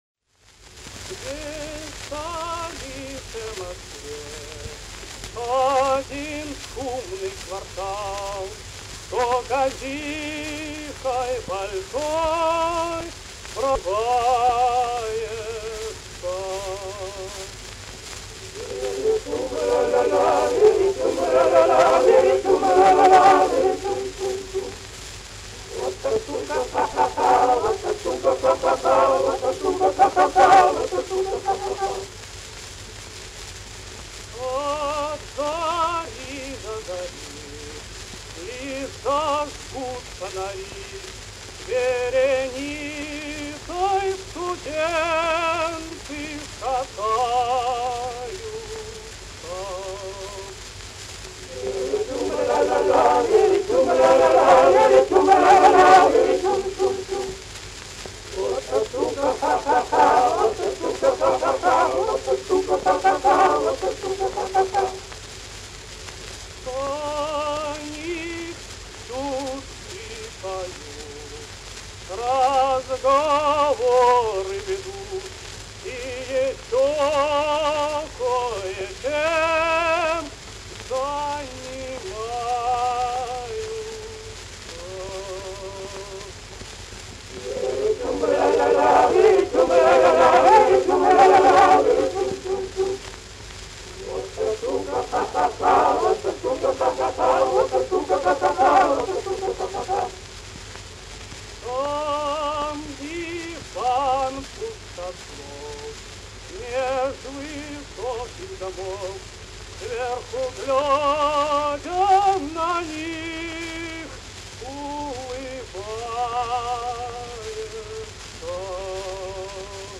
С «тумбой» — это пожалуйста, Хотя, быть может, следующая «старинная студенческая песня», записанная в конце 1909 года хором студентов Императорского Московского университета, отчасти «Тарарабумбию» напоминает: не очень эффектные, нарочито медленные, но при этом всё же озорные, «на грани», куплеты, перемежаемые взрывным припевом из восьми строк — с «тумбой», конечно же, куда ж без неё.
hor-studentov-imperatorskogo-moskovskogo-universiteta---starinnaya-studencheskaya-pesnya.mp3